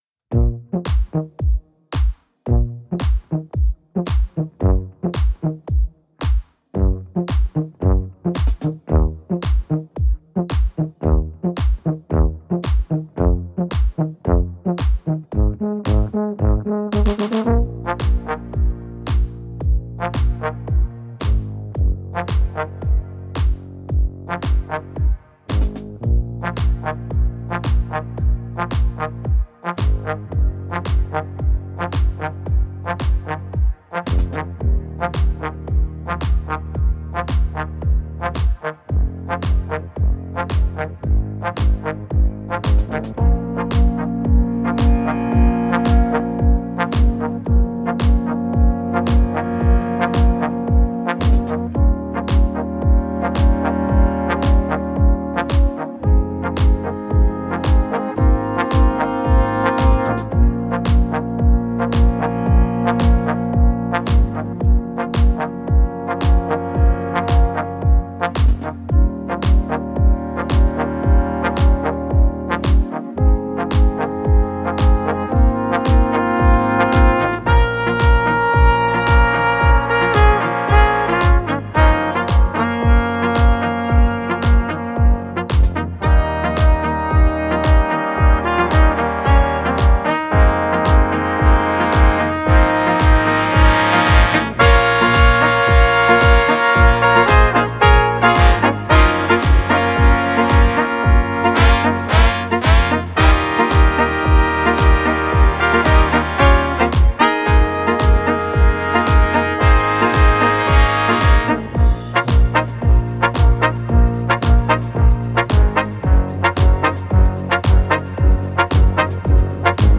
Unterhaltungsmusik für Blasorchester Dauer